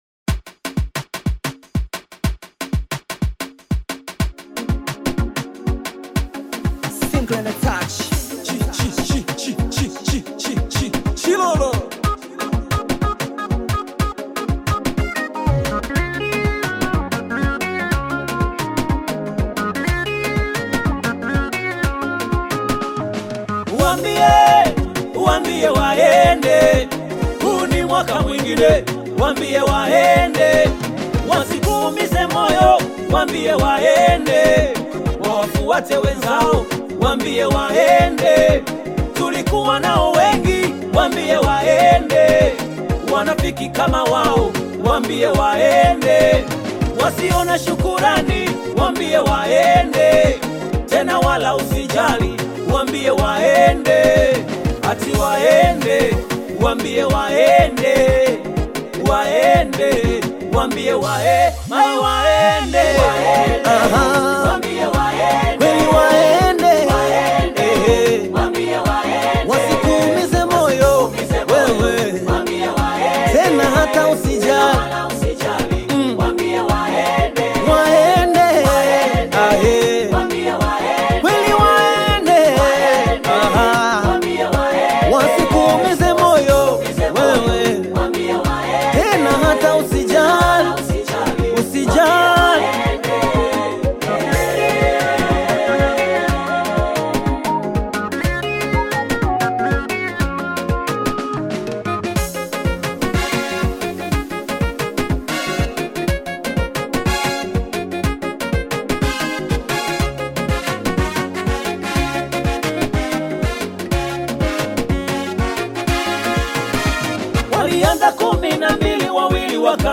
Tanzanian Gospel artist, singer and songwriter
Gospel song